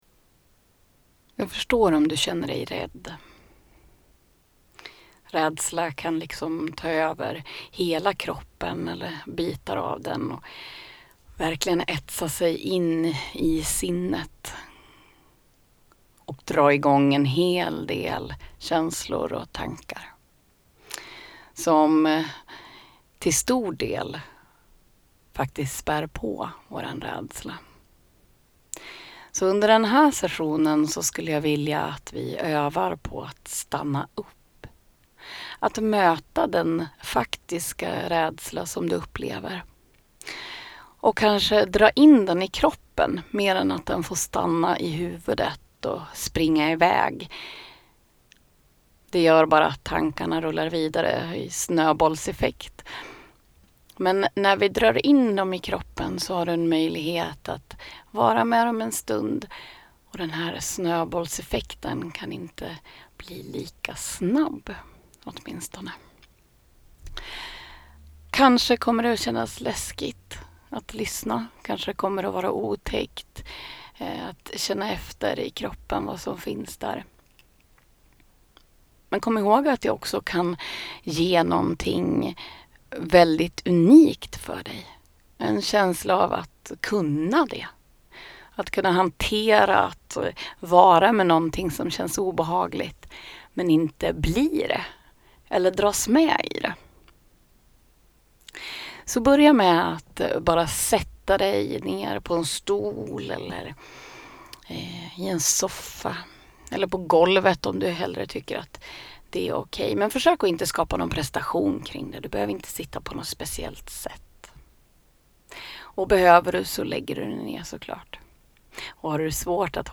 Avslappning_radsla_generell.mp3